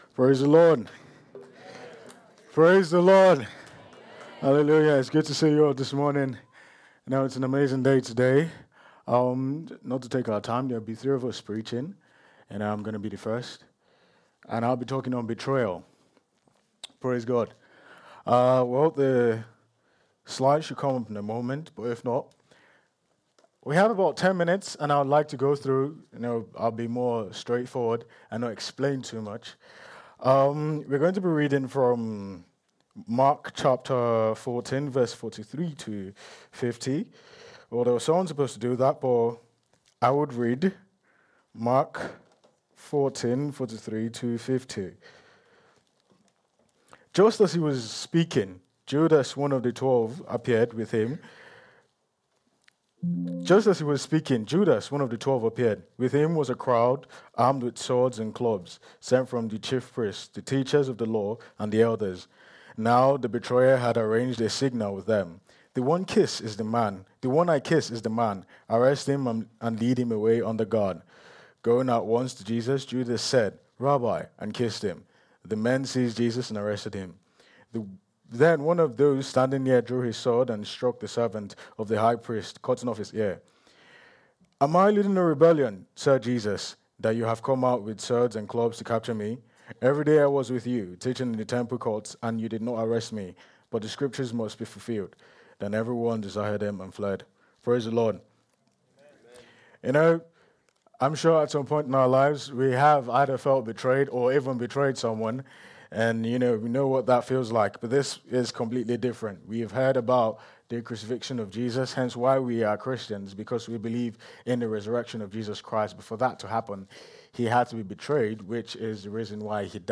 Download The Triple Threat | Sermons at Trinity Church